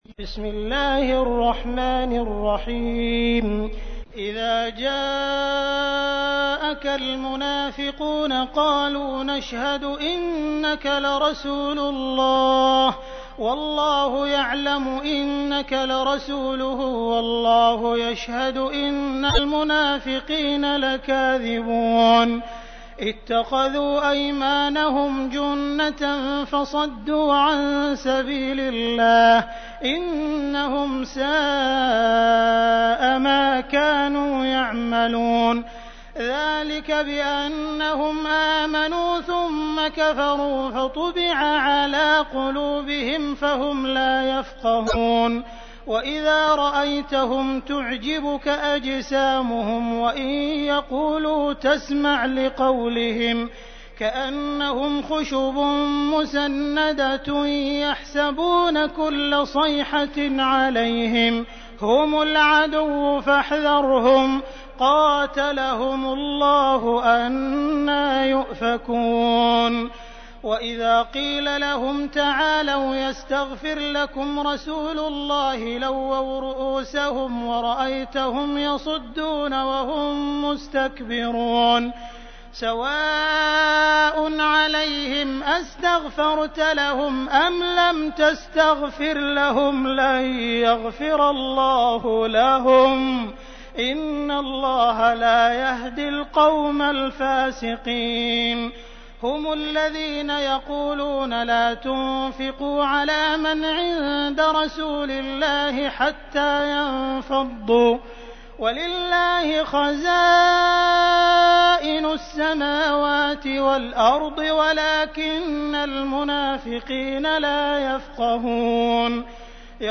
تحميل : 63. سورة المنافقون / القارئ عبد الرحمن السديس / القرآن الكريم / موقع يا حسين